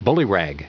Prononciation du mot bullyrag en anglais (fichier audio)
Prononciation du mot : bullyrag